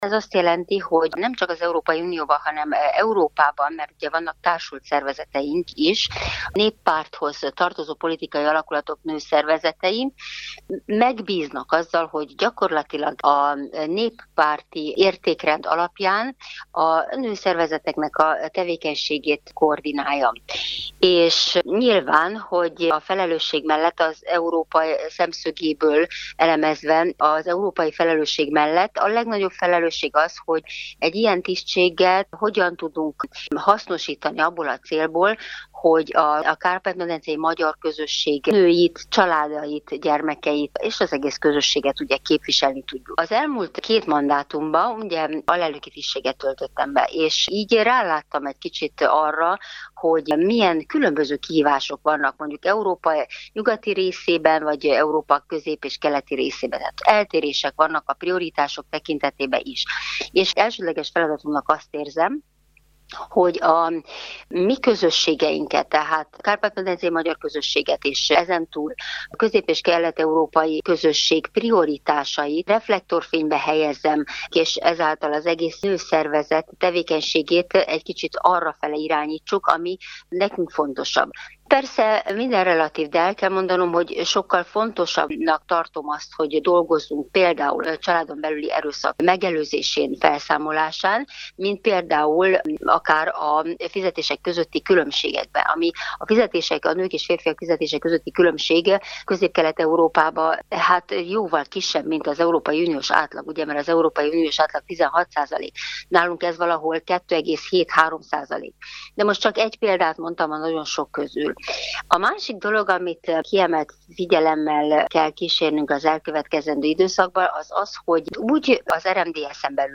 Biró Rózália nyilatkozott rádiónknak.